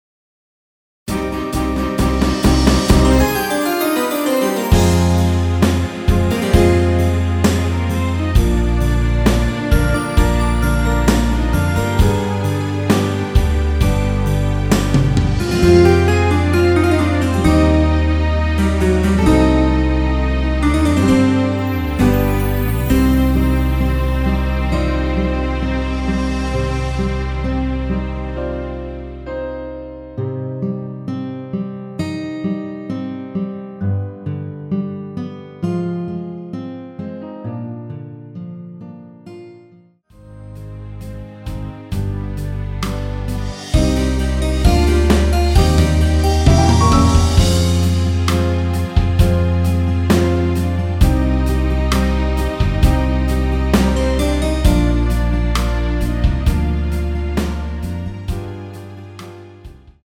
원키에서(-1)내린 MR입니다.
Cm
◈ 곡명 옆 (-1)은 반음 내림, (+1)은 반음 올림 입니다.
앞부분30초, 뒷부분30초씩 편집해서 올려 드리고 있습니다.